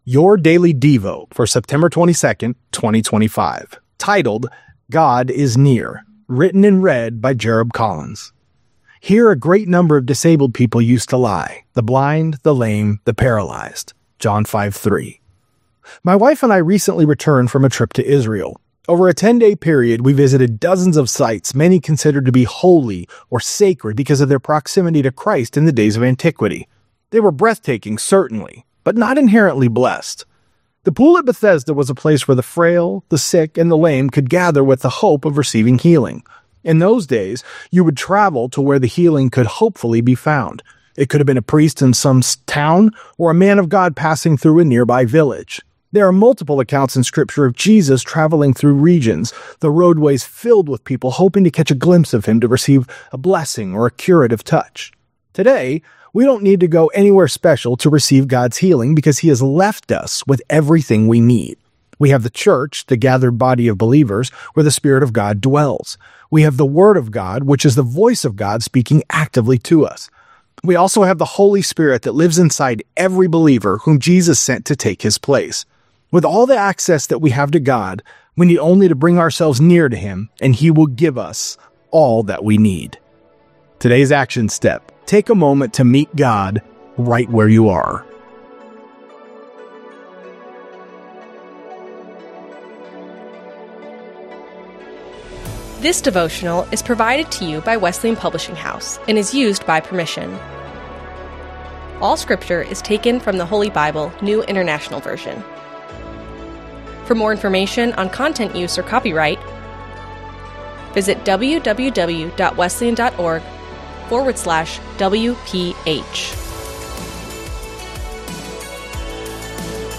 Daily Devotionals